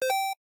score-reached.mp3